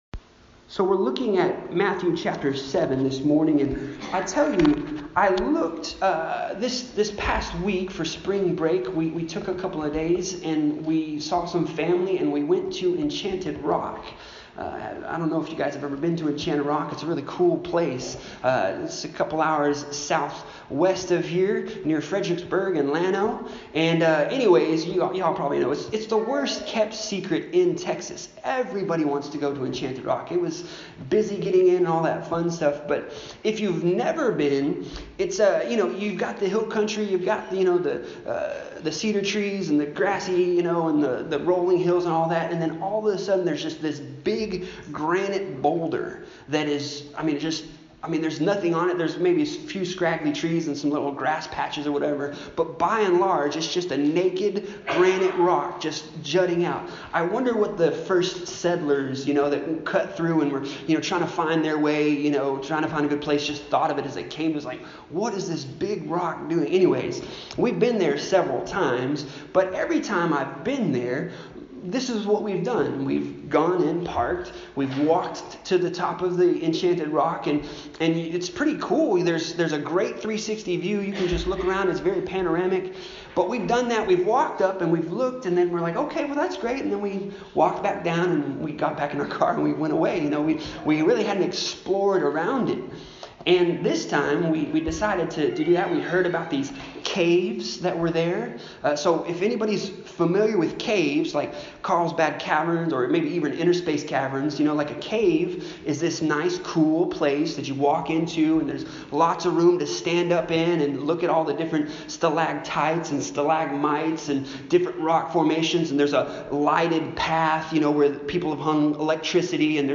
Passage: Matthew 7:13-23 Service Type: Sunday Morning